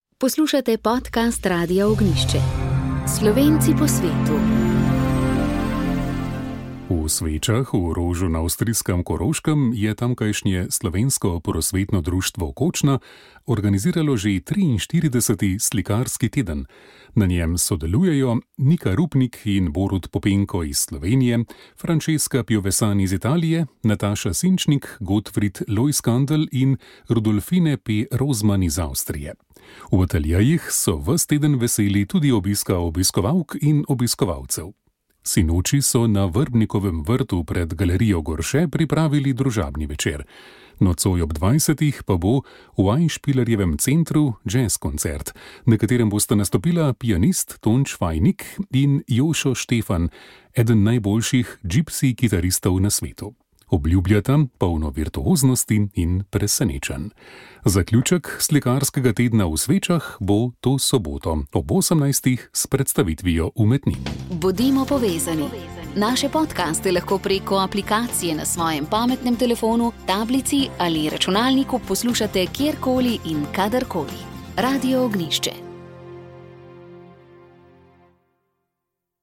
Lepe misli slovenskih škofov, njihovi spomini na božič, praznična glasba in vzpodbuda za pravo praznovanje.